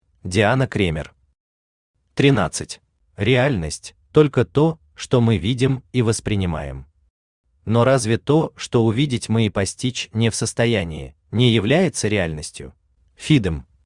Aудиокнига 13 Автор Диана Кремер Читает аудиокнигу Авточтец ЛитРес.